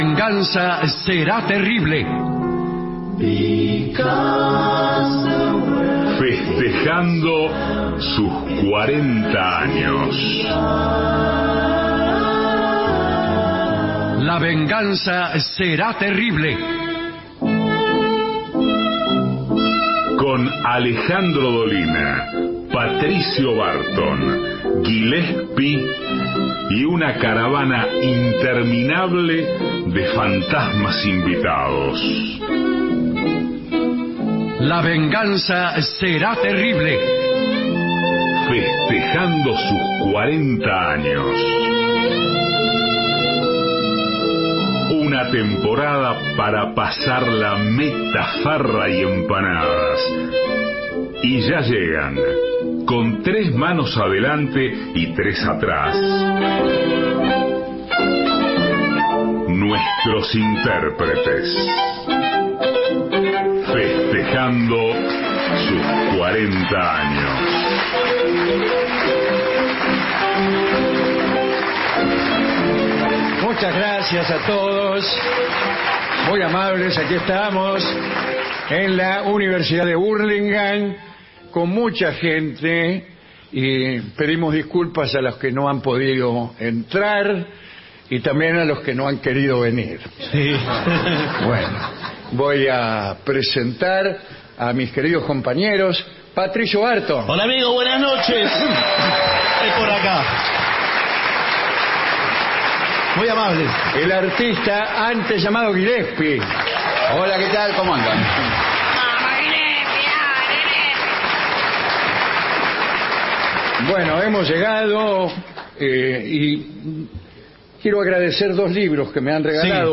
todo el año festejando los 40 años Feria del Libro, Universidad de Hurlingam Alejandro Dolina